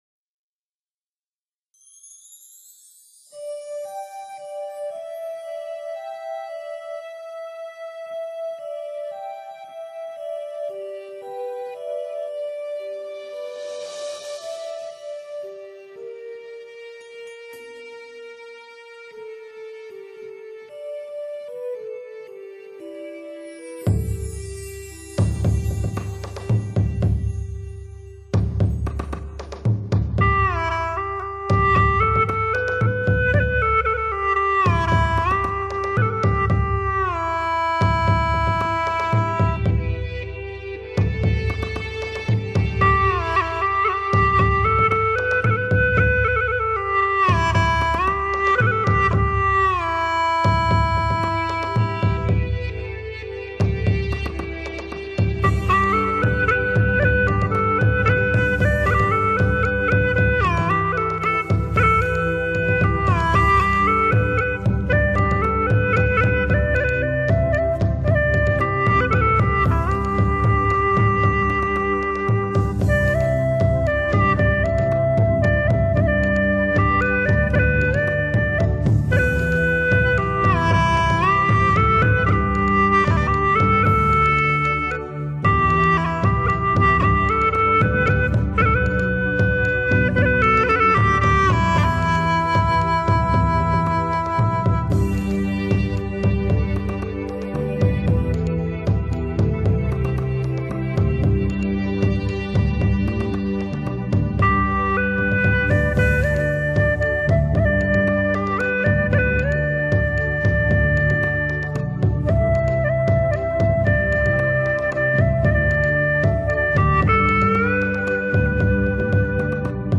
录音十分细腻，线条明快，质感晶莹剔透，
低频（暗涌）甚多，高频空气感深阔，气氛和音色都十分出色。
曲与曲之间音压平衡度都很平均一致，
通透细致的特色近乎百分百保存，